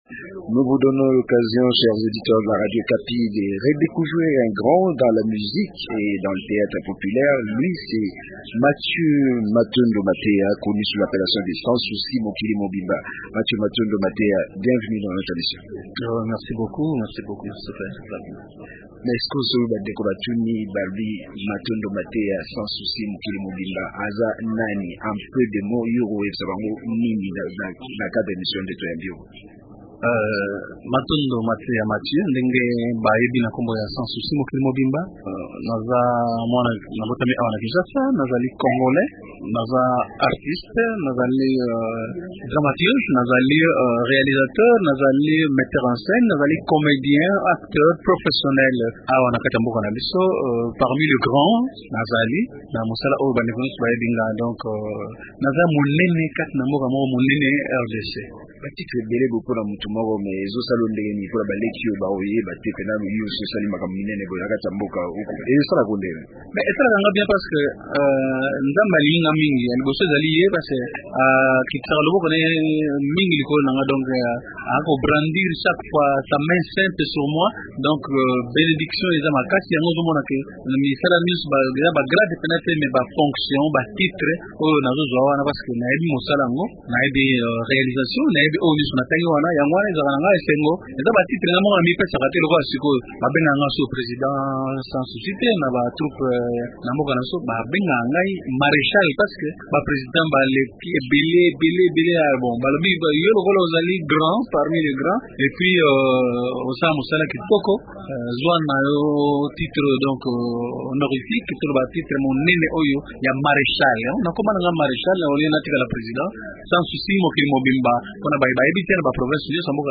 Invité d’Okapi Souvenir, l’artiste délie sa langue et révèle ses projets de réaliser un autre album dans les jours qui viennent.